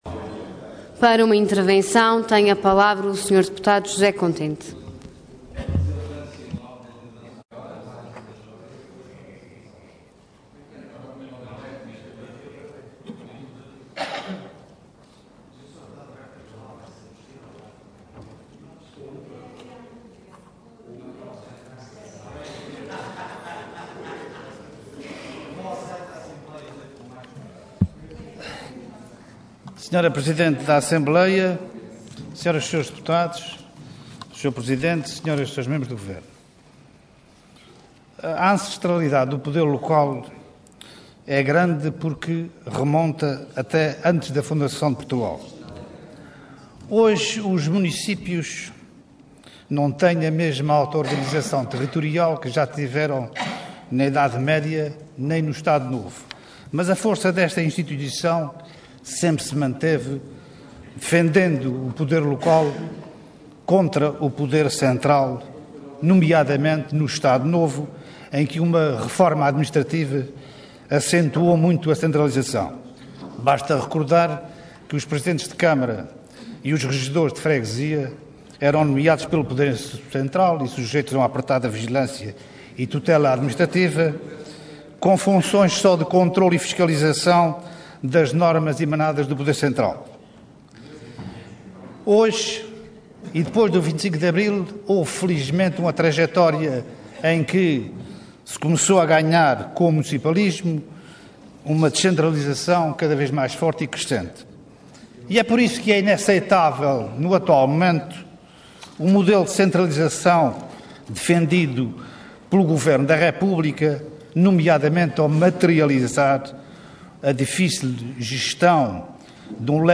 Detalhe de vídeo 19 de março de 2013 Download áudio Download vídeo Diário da Sessão Processo X Legislatura Plano e Orçamento 2013 - O poder local nos Açores: Perspectivas e desafios. Intervenção Intervenção de Tribuna Orador José Contente Cargo Deputado Entidade PS